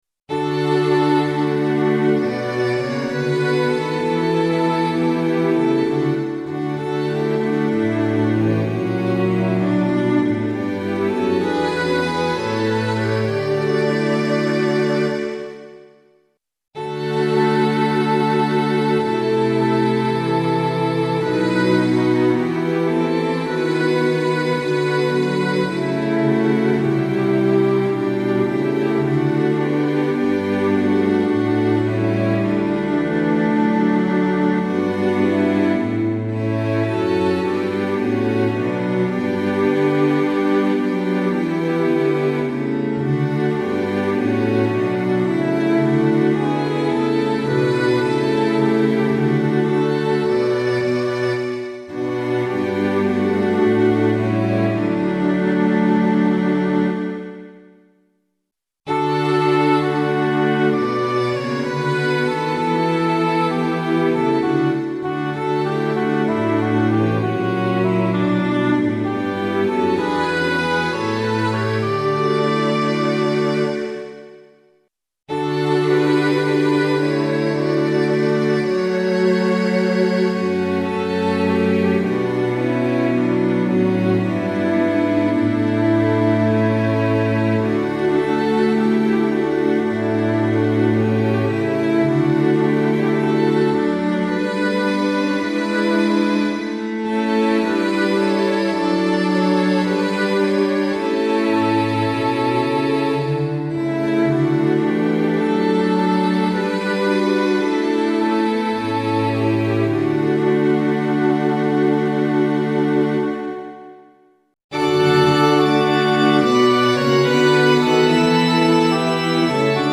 混声四部合唱+器楽 Four-part mixed chorus with Instruments
Sample Sound ：参考音源 - 様々な音色の組み合わせによる
DL Fl+Str Fl+Str Ob+Str Fl-Str Ob-Org-Str